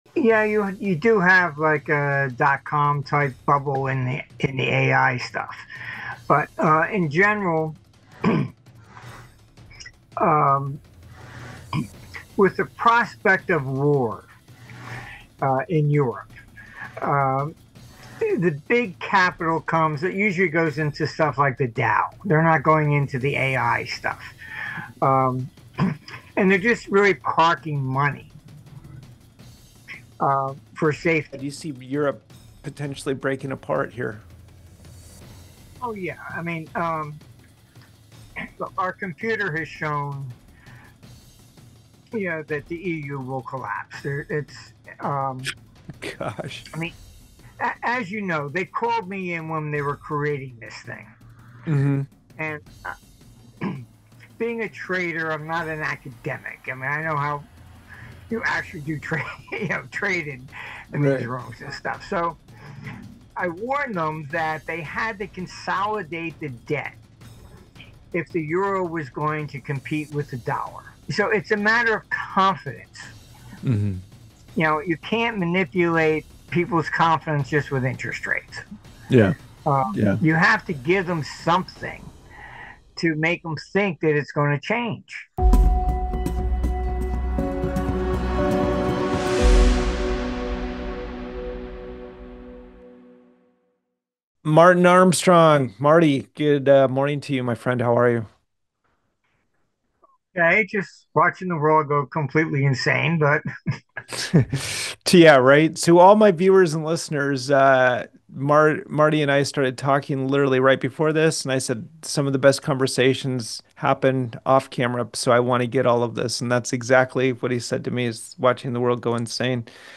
Economist and forecaster Martin Armstrong (ArmstrongEconomics) joins Natural Resource Stocks to break down capital flows, the EU’s structural flaws, banking